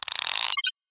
gearup.ogg